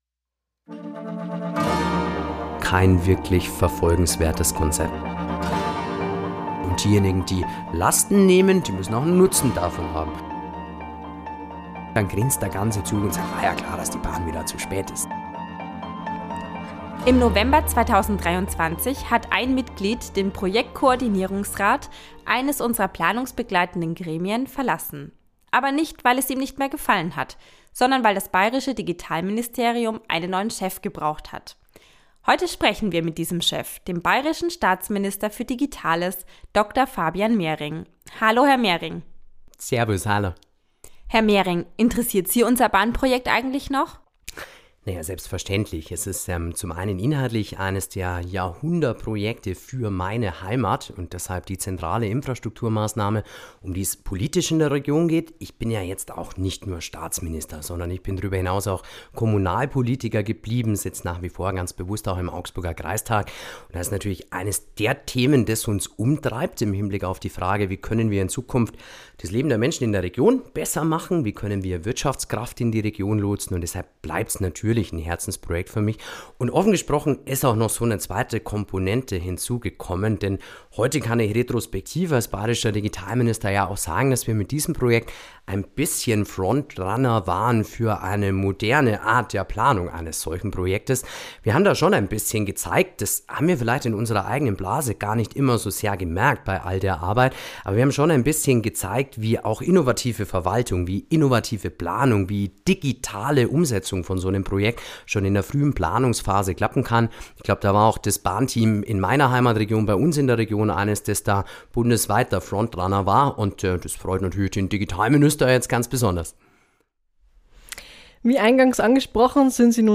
Ein Gespräch mit den obersten bayerischen Digitalisierer über die Vorteile von Faxgeräten, wie wir in 15 Jahren im Zug sitzen werden und worüber er sich mit seinem Platznachbarn im Ministerrat, Verkehrsminister Christian Bernreiter, unterhält.